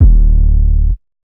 Big boi 808 (D).wav